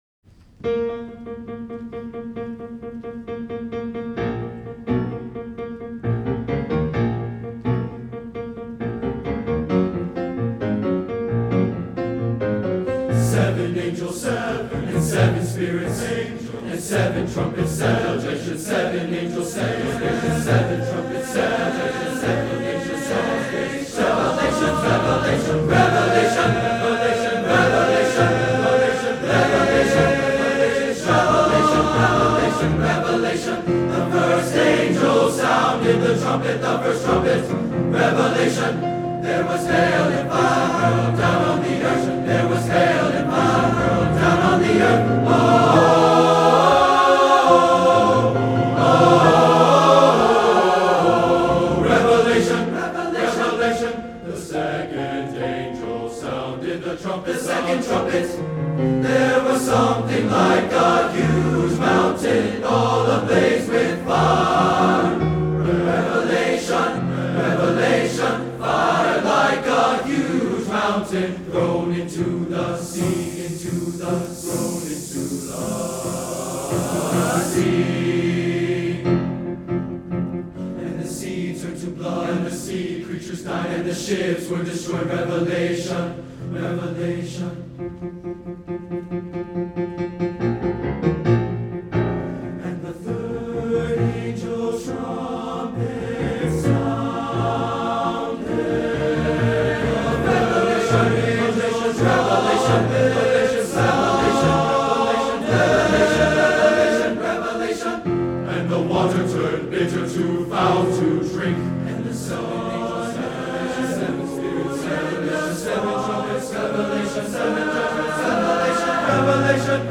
Voicing: TTBB
Instrumentation: piano